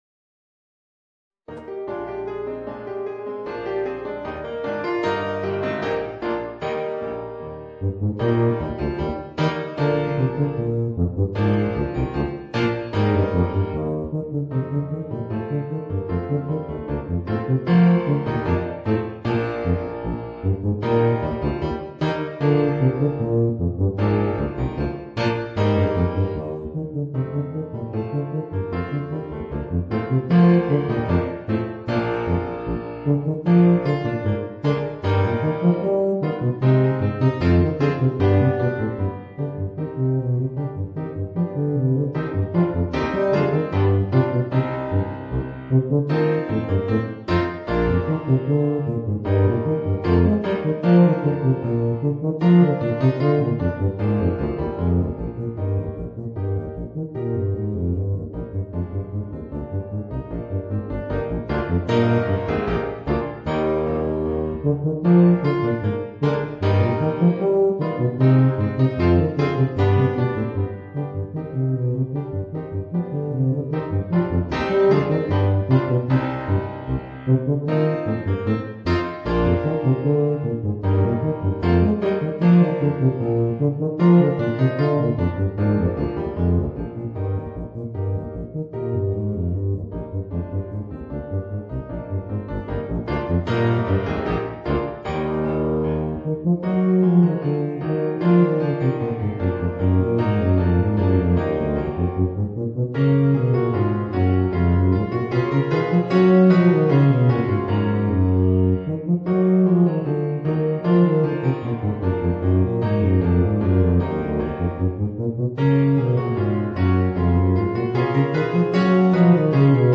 Voicing: Bb Bass and Piano